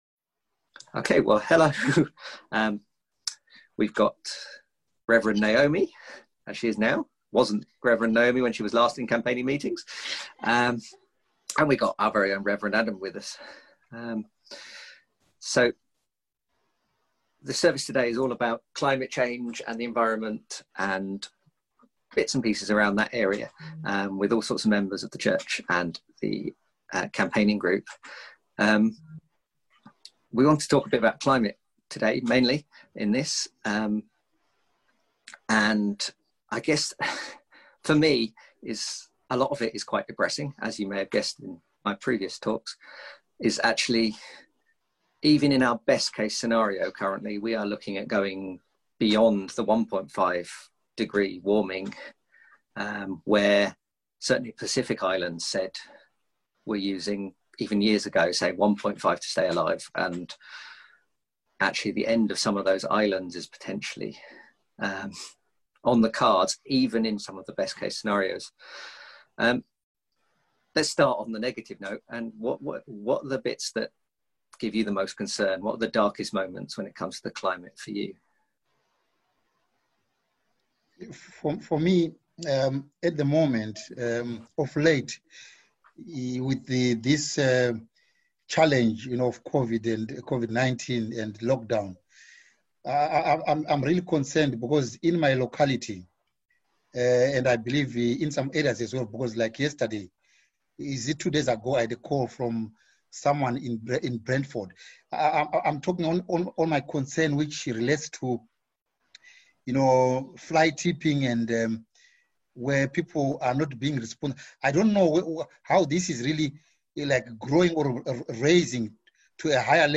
9th Aug Environment Worship at Home Psalms Watch Listen Save Something a little different this week